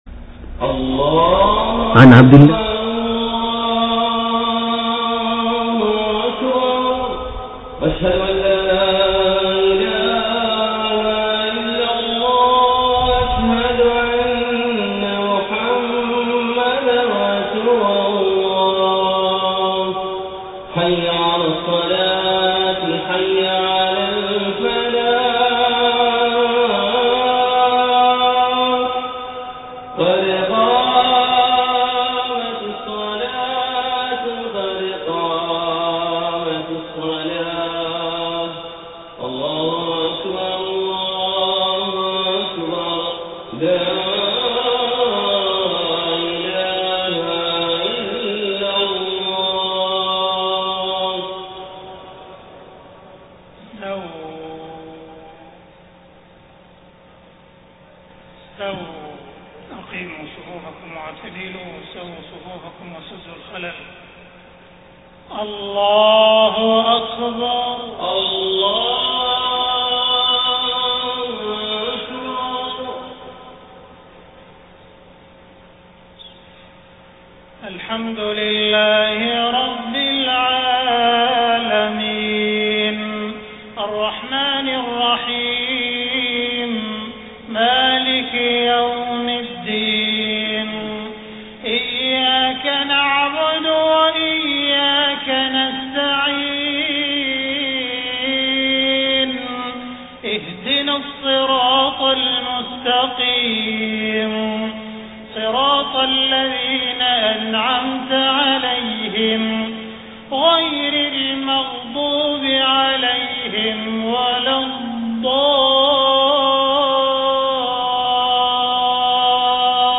صلاة المغرب 27 محرم 1430هـ سورة البروج كاملة > 1430 🕋 > الفروض - تلاوات الحرمين